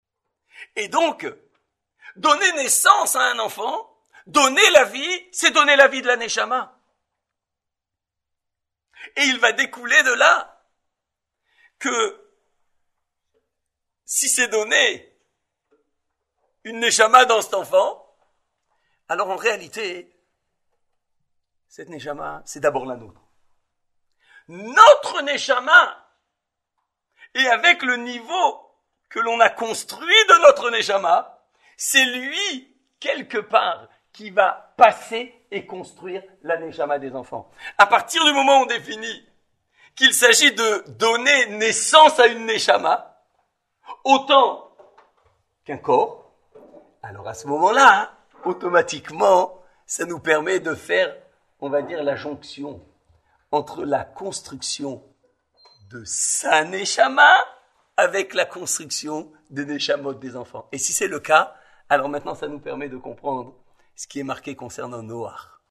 Nous étions ce Motsé Shabbath Mikets 5 Téveth 5784 – 16 décembre 2023 au Igoud ‘Harédi à Paris. Prou OuRevou est connu comme étant la première Mitzva de la Torah que HASHEM a ordonné à Adam et ‘Hava (Bereshith 1, 28). Elle constitue tout un programme comprenant tant d’enjeux que de conséquences.